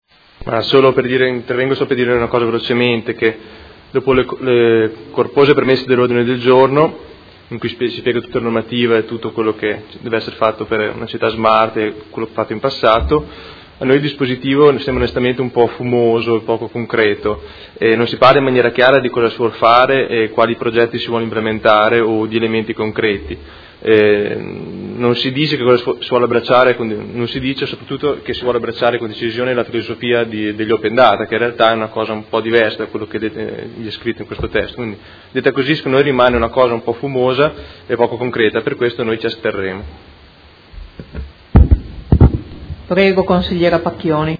Seduta del 29/09/2016 Dibattito. Ordine del Giorno presentato dai Consiglieri Fasano, Pacchioni, Arletti, Stella, Malferrari, Poggi, Liotti, Baracchi, Bortolamasi e Trande (P.D.) avente per oggetto: Supporto alle politiche pubbliche attraverso l’utilizzo e la condivisione di banche dati